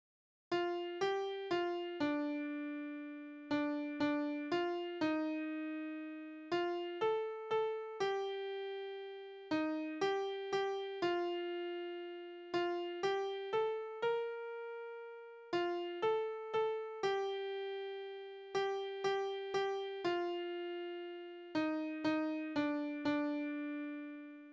Balladen: Er findet seine Liebste sterbend wieder
Tonart: B-Dur
Taktart: 4/4
Tonumfang: kleine Sexte
Besetzung: vokal